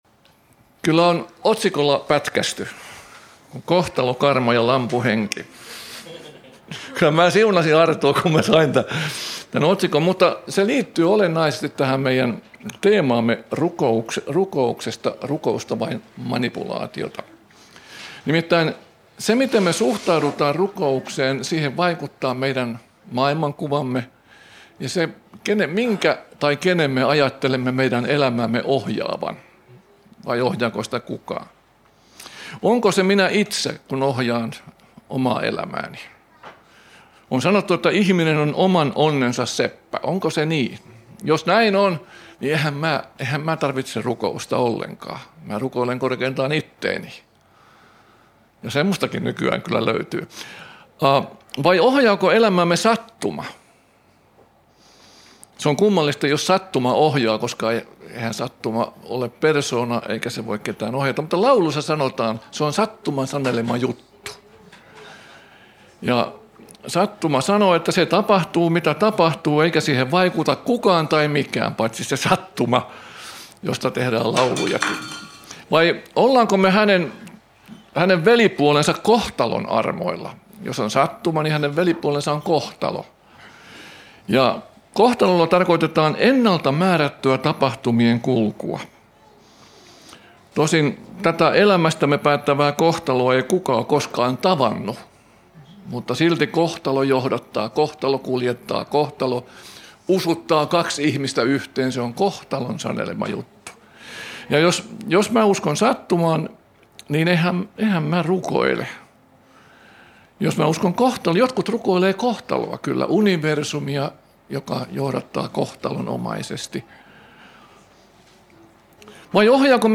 Jyväskylän Satamaseurakunnan opetuksista koottu podcast.